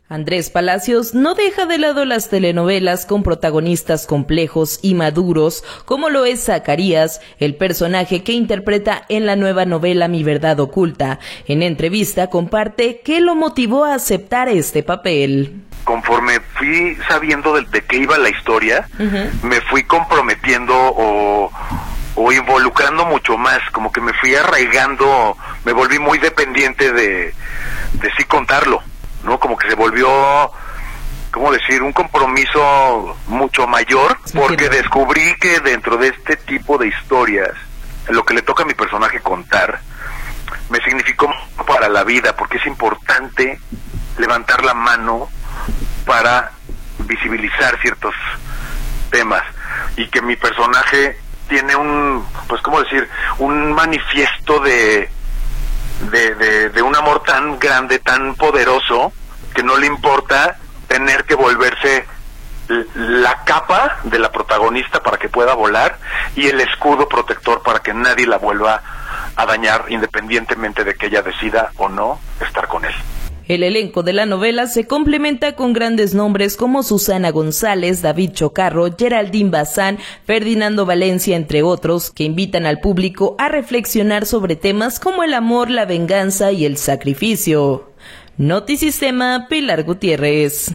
Andres Palacios, no deja de lado las telenovelas con protagonistas complejos y maduros, como lo es “Zacarías”, el personaje que interpreta en la nueva novela “Mi Verdad Oculta”, en entrevista comparte que lo motivó a aceptar este papel.